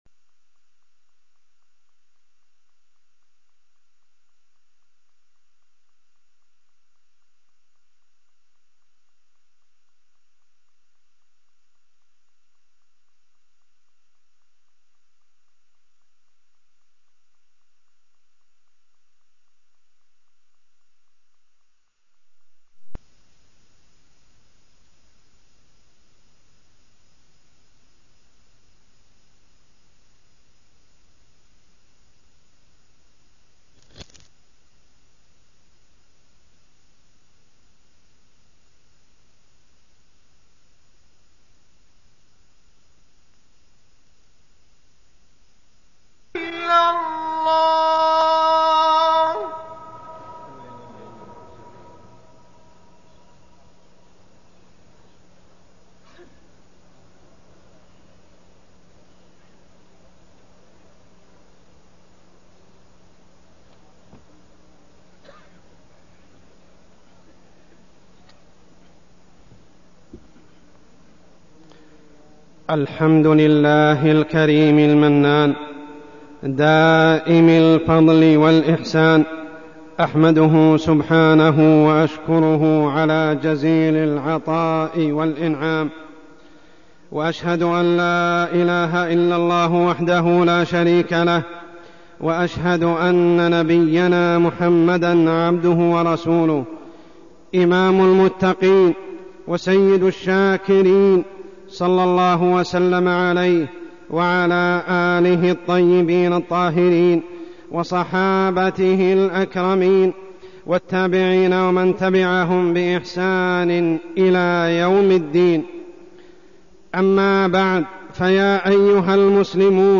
تاريخ النشر ٥ شعبان ١٤١٨ هـ المكان: المسجد الحرام الشيخ: عمر السبيل عمر السبيل تجديد الشكر لنزول المطر